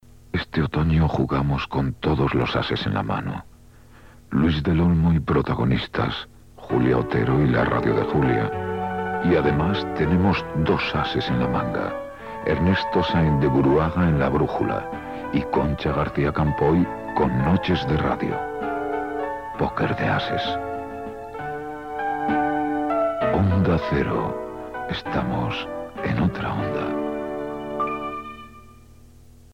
2f614faa8b50b3d4ed275a4a5cf0c2af52442bc8.mp3 Títol Onda Cero Radio Emissora Onda Cero Barcelona Cadena Onda Cero Radio Titularitat Privada estatal Descripció Promo de la programació d'Onda Cero "Póker de ases".